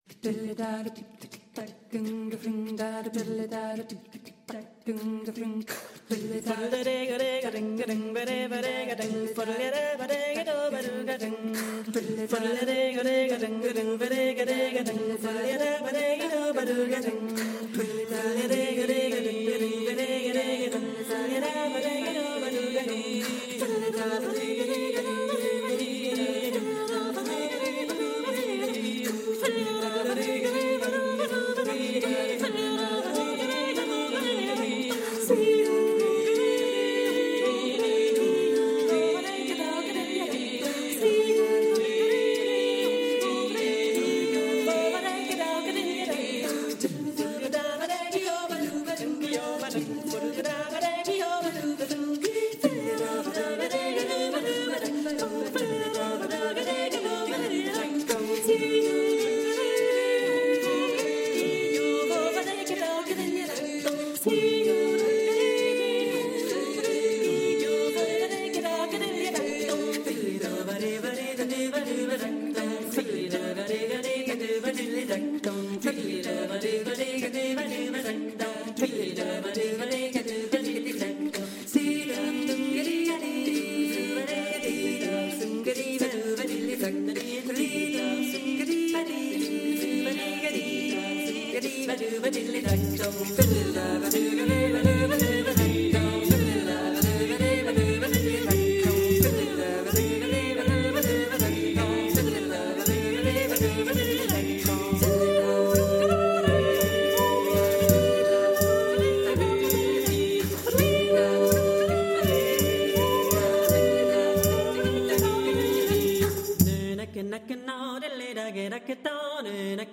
Melodie e danze delle minoranze linguistiche
Sjaella (“anima” in svedese) è un ensemble vocale formato da giovani donne, formatosi a Lipsia nel 2005.